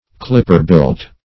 Clip"per-built`, a.